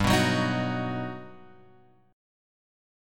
G+M7 chord {3 2 4 x 4 2} chord